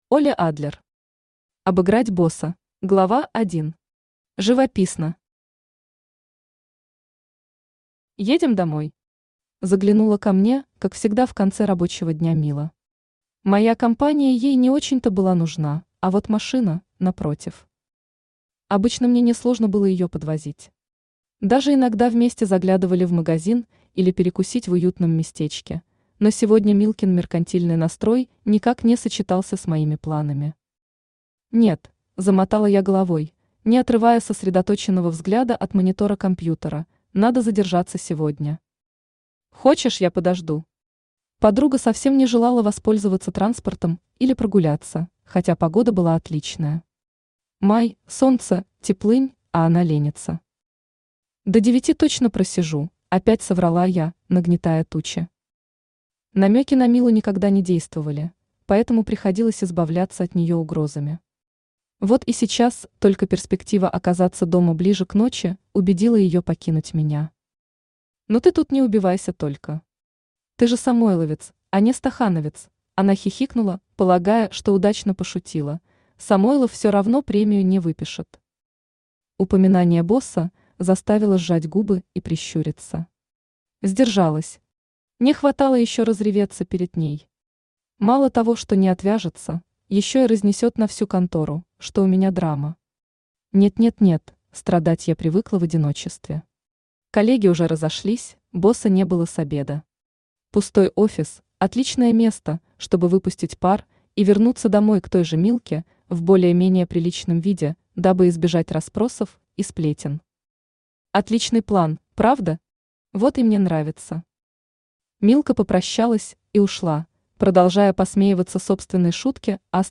Aудиокнига Обыграть босса Автор Оле Адлер Читает аудиокнигу Авточтец ЛитРес.